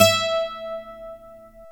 Index of /90_sSampleCDs/Roland L-CD701/GTR_Steel String/GTR_18 String
GTR 12 STR0R.wav